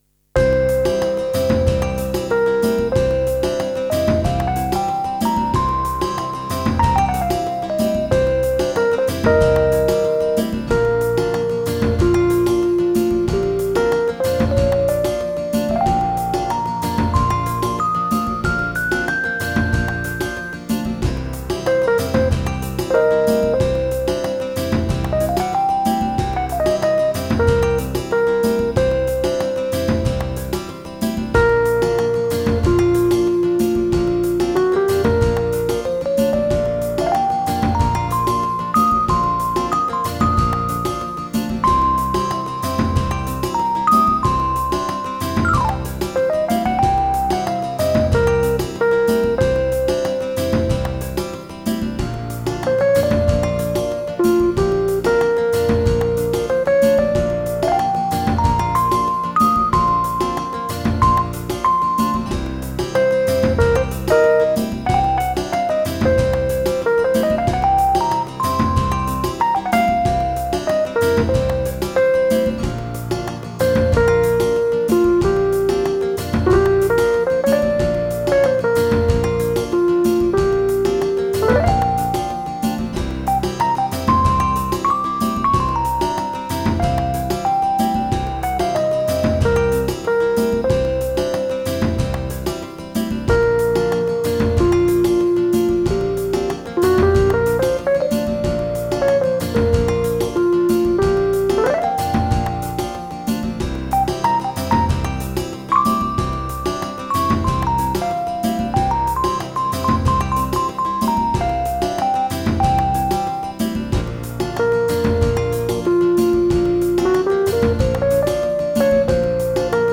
Latin Tanz Lied.